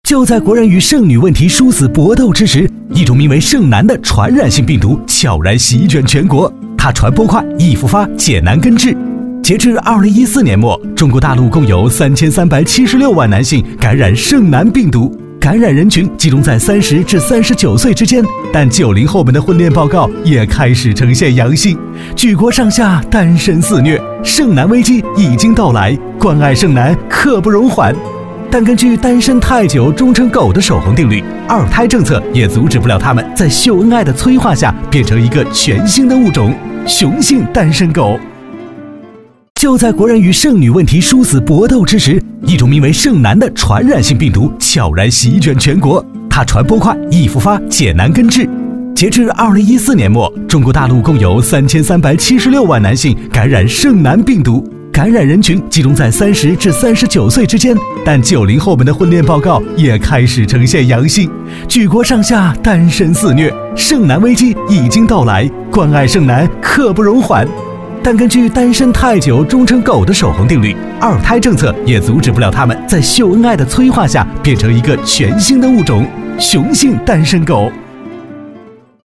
国语青年大气浑厚磁性 、沉稳 、科技感 、积极向上 、时尚活力 、男广告 、400元/条男S347 国语 男声 广告-奔驰C级-汽车宣传片-品质大气 大气浑厚磁性|沉稳|科技感|积极向上|时尚活力